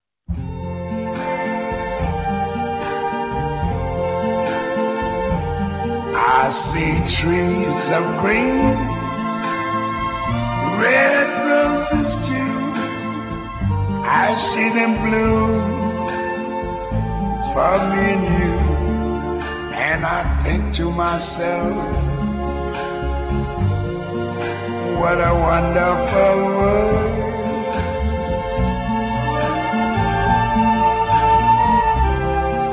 По следующим звуковым файлам вы сможете оценить качество работы аудио кодека:
Исходная музыка (16 кГц, моно)
Скорость кодирования 16 кбит/с при нулевых потерях.